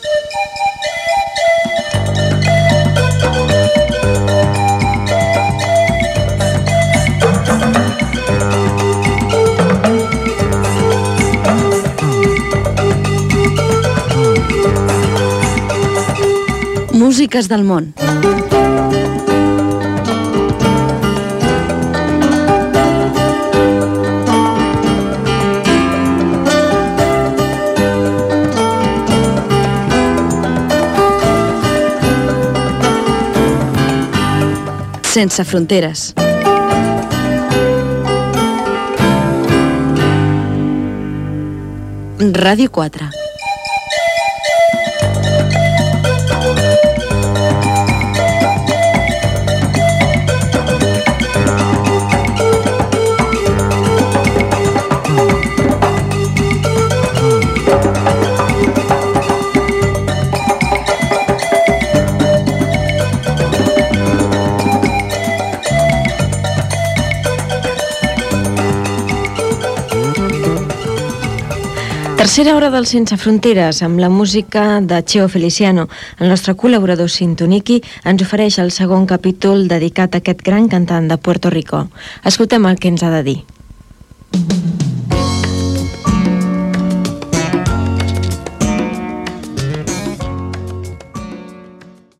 Sintonia del programa amb identificació i presentació de l'espai dedicat a Cheo Feliciano.
Musical